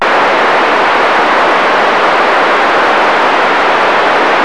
sounds like data being passed.